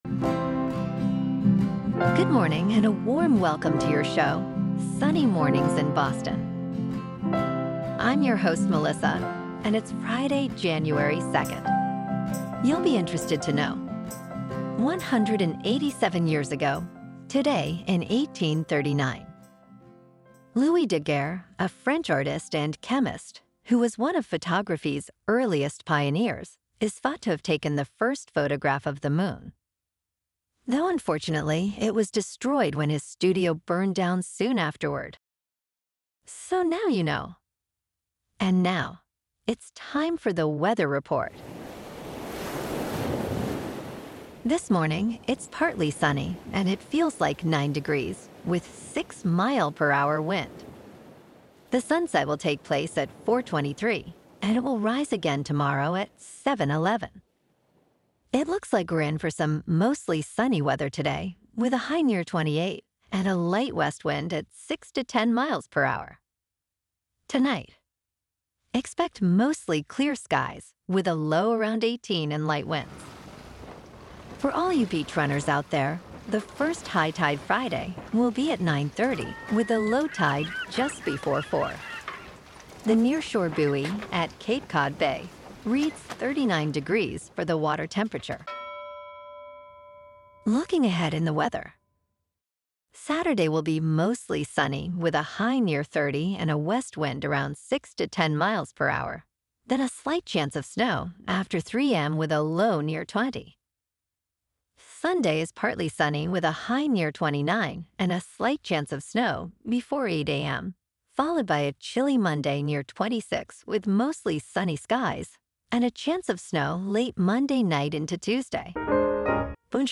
Daily Boston News, Weather, Sports.
The #1 Trusted Source for AI Generated News™